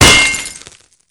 wrench2.wav